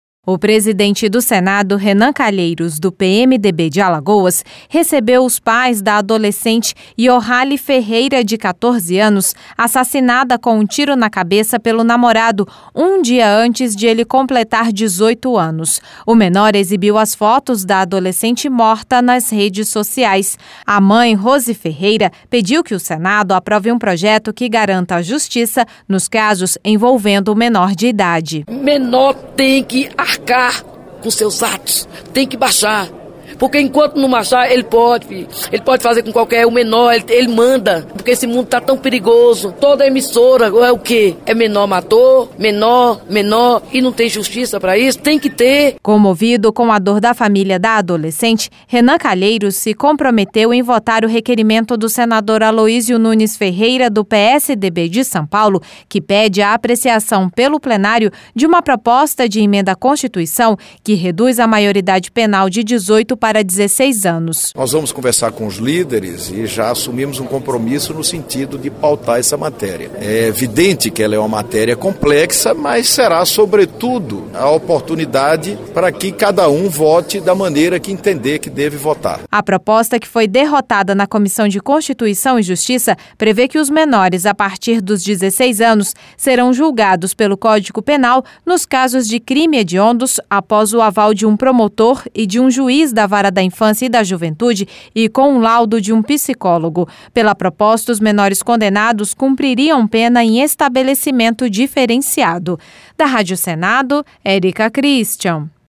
LOC: O SENADO DEVE RETOMAR A DISCUSSÃO DA REDUÇÃO DA MAIORIDADE PENAL.